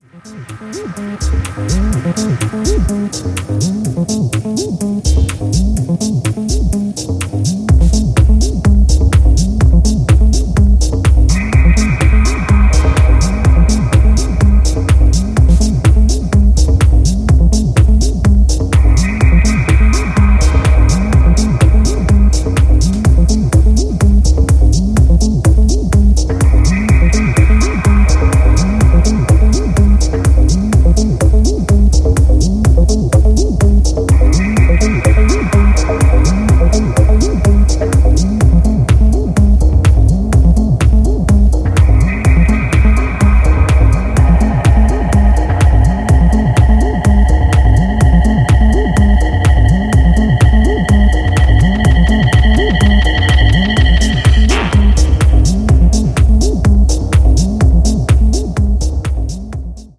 Electronix Techno Acid